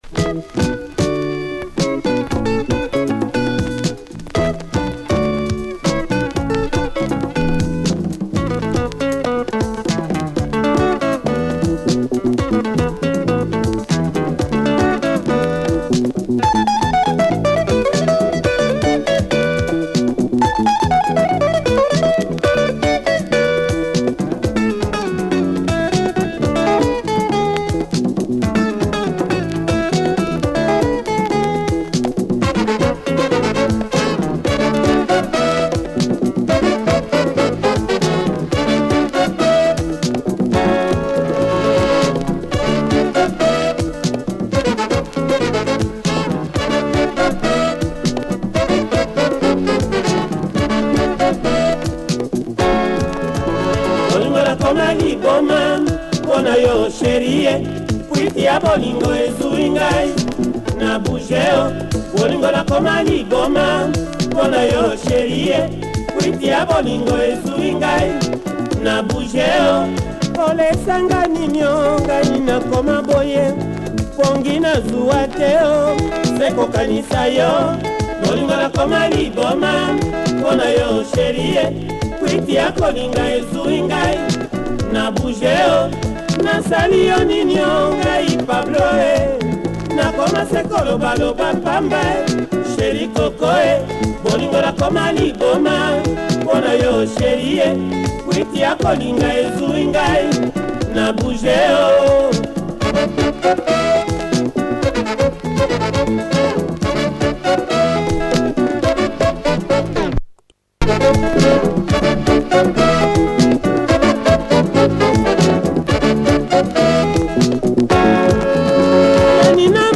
Nice 80s Lingala by this influential group.